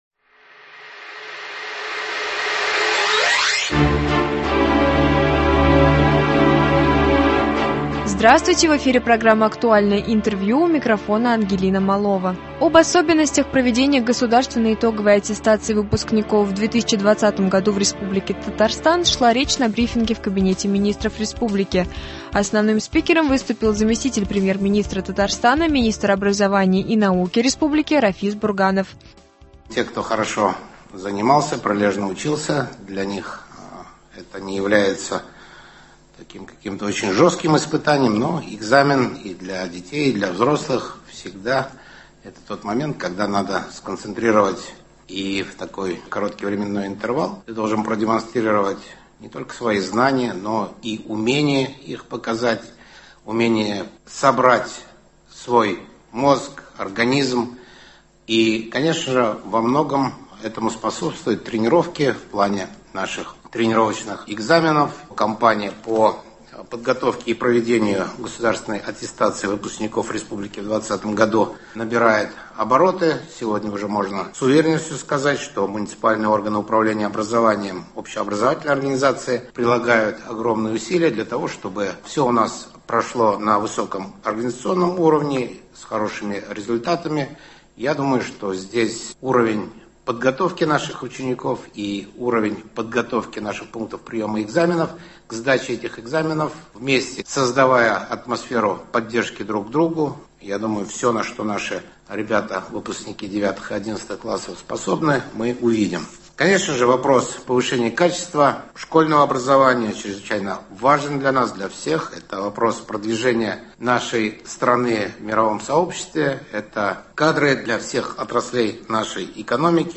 Актуальное интервью. 11 марта.